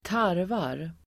Uttal: [²t'ar:var]